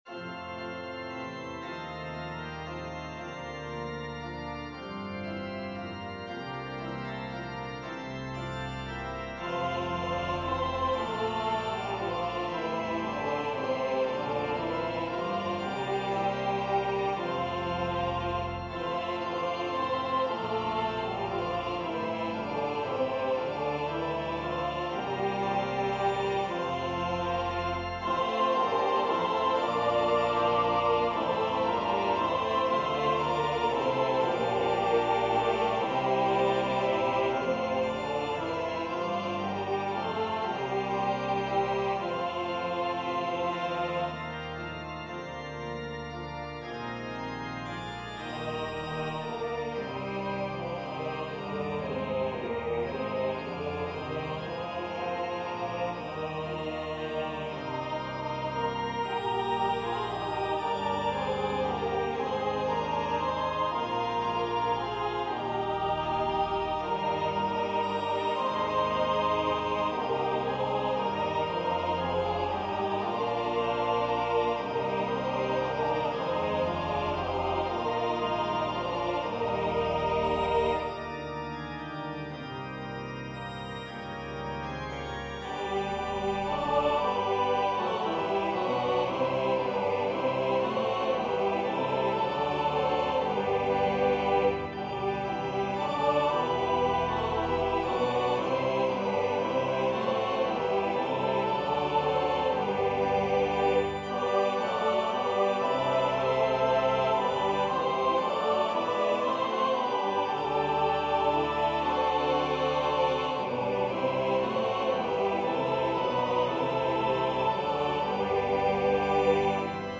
Voicing/Instrumentation: SATB , Organ/Organ Accompaniment